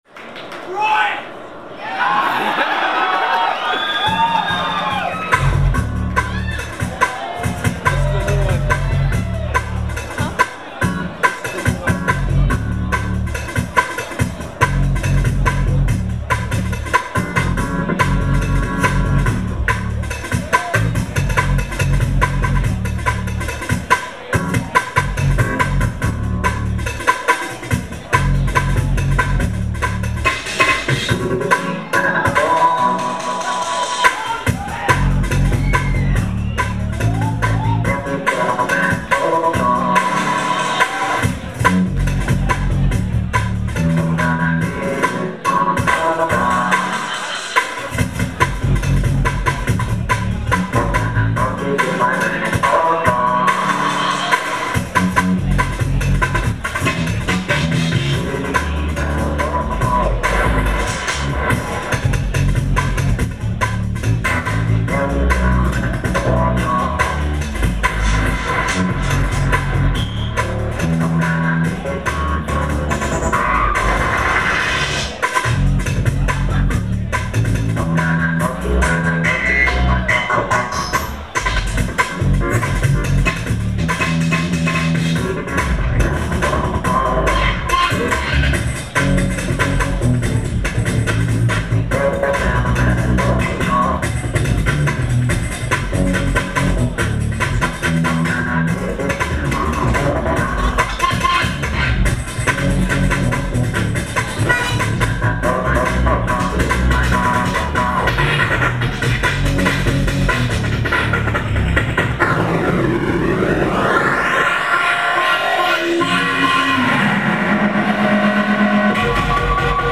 location San Francisco, USA